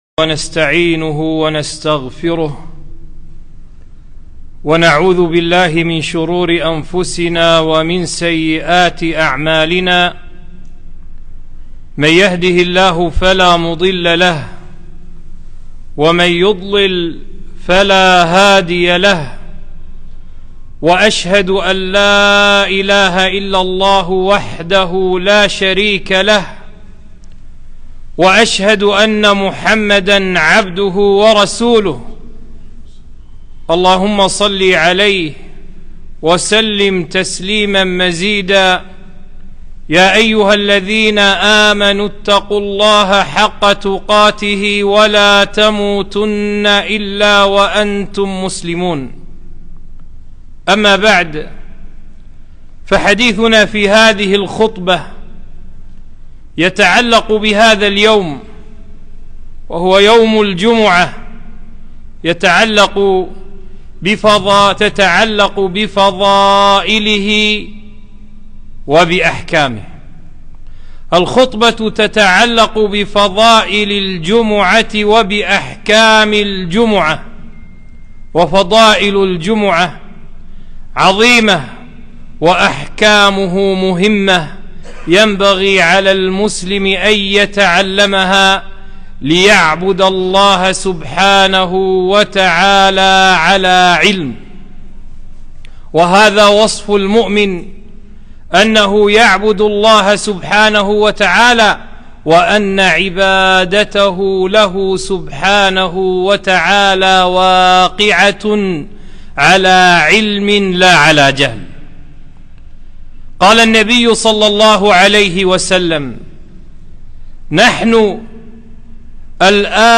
خطبة - يوم الجمعة فضائل وأحكام